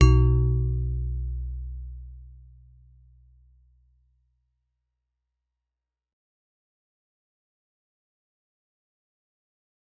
G_Musicbox-B1-f.wav